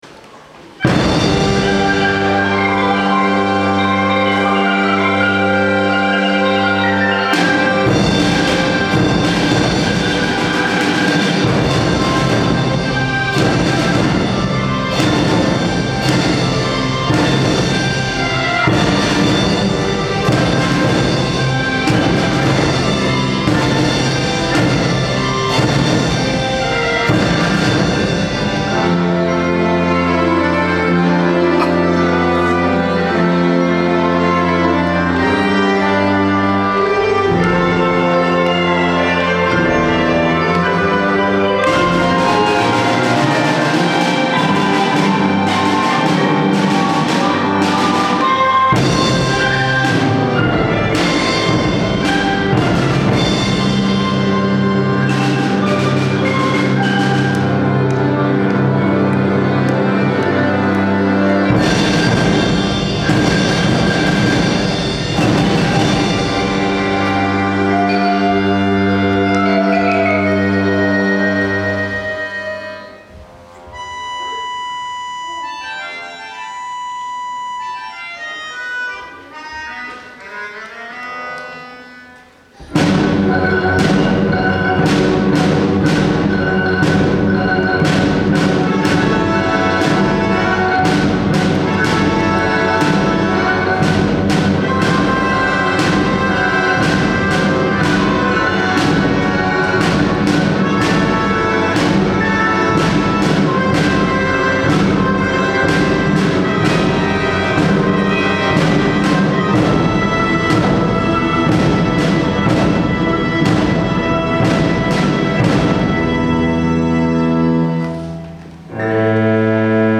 始まりから大迫力の演奏に、子どもたちは目をまん丸にして、大空のリーダーの姿を見ます。
大空のリーダーによってすべての楽器が活かされ、それぞれが自分の役割を担い、見事に響き合う演奏は、１〜５年生の心をつかみ、アンコールを巻き起こしました。